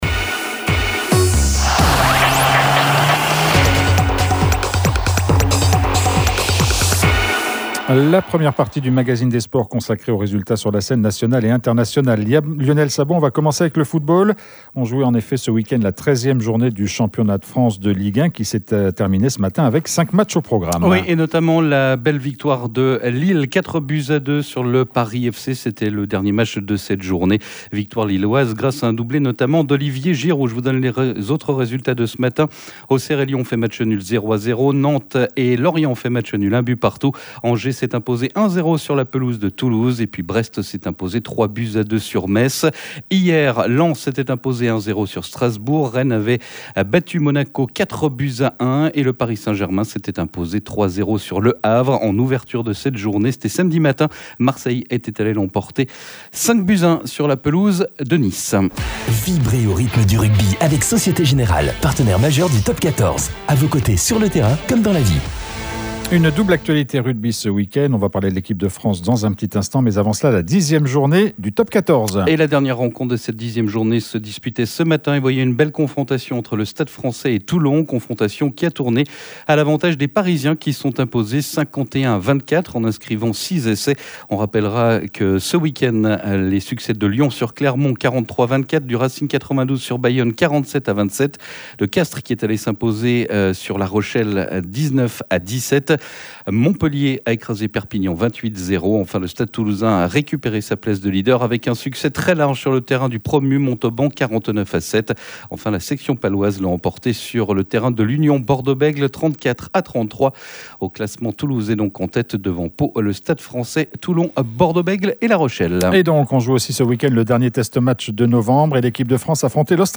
étaient avec nous en studio